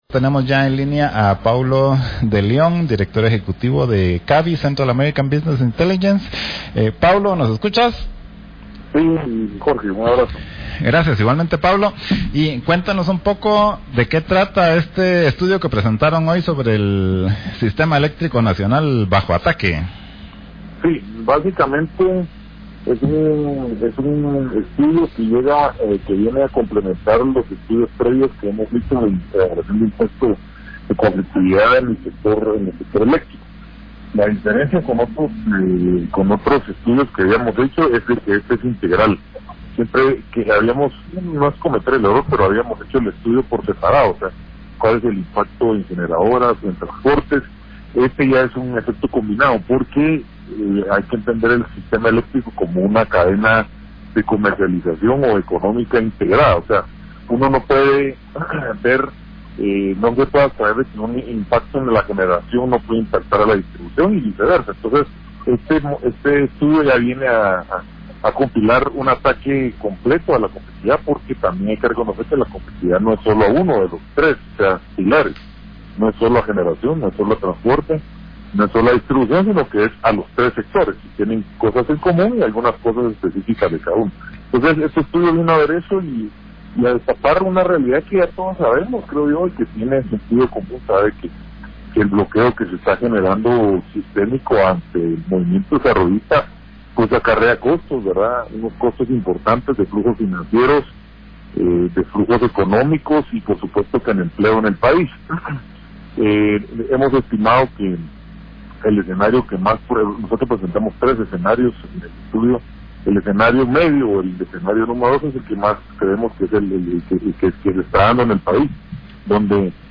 TODO A PULMON / LIBERTOPOLIS: Entrevista